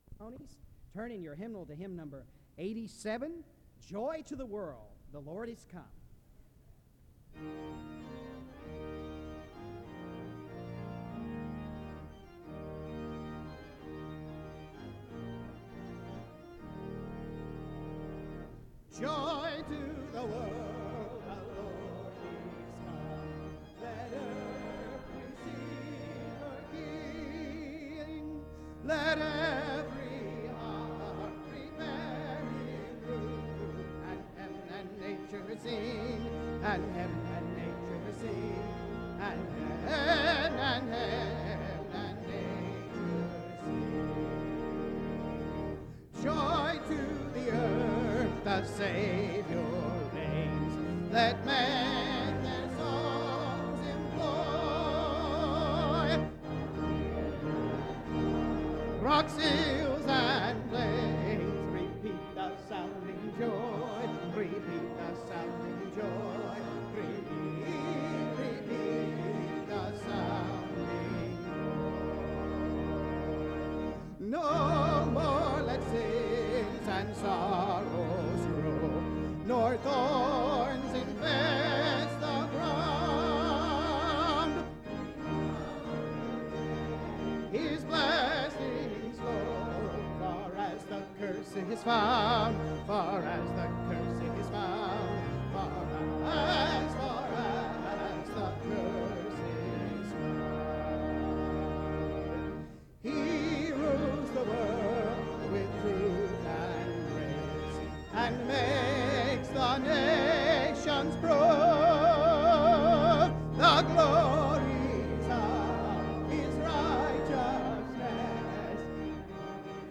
SEBTS Commencement
SEBTS Chapel and Special Event Recordings